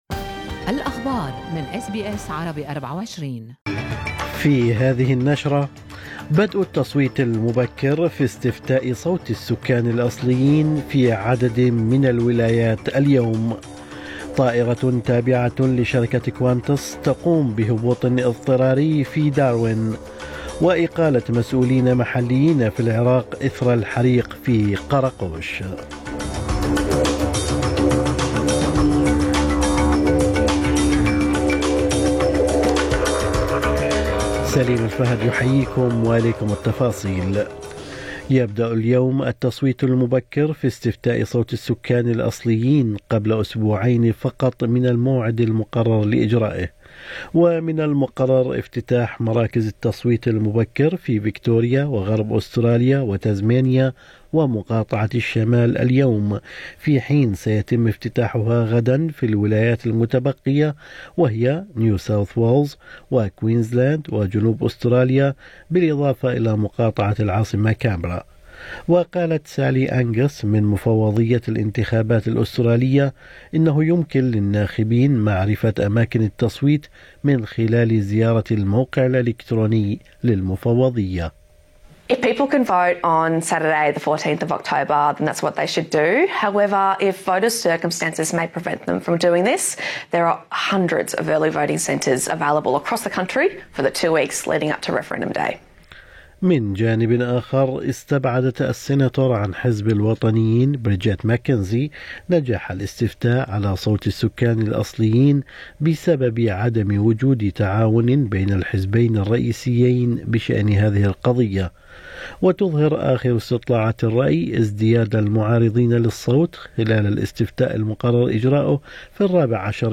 نشرة أخبار الصباح 2/10/2023